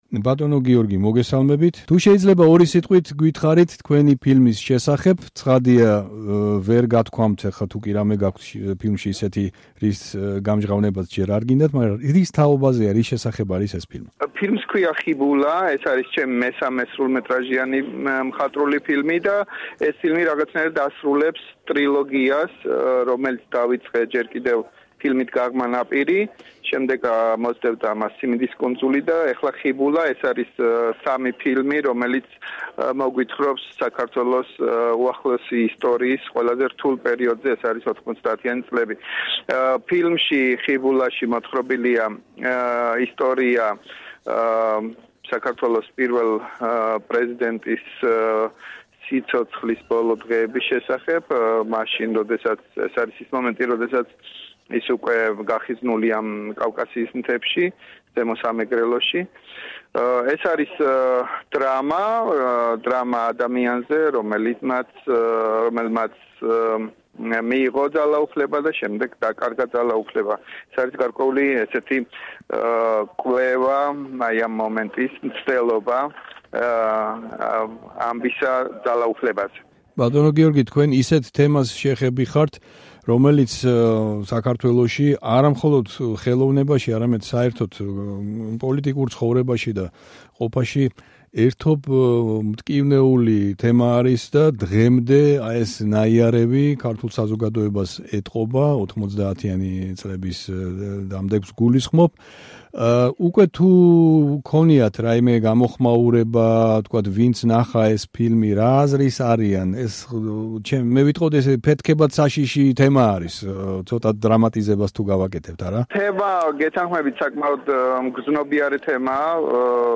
კარლოვი ვარის 52-ე საერთაშორისო კინოფესტივალზე გაიმართა საპრემიერო ჩვენება გიორგი ოვაშვილის ფილმისა „ხიბულა“, რომელიც ყოფილი პრეზიდენტის, ზვიად გამსახურდიას სიცოცხლის უკანასკნელ დღეებს ეხება. რადიო თავისუფლება კარლოვი ვარიში დაუკავშირდა ქართველ რეჟისორს.
საუბარი გიორგი ოვაშვილთან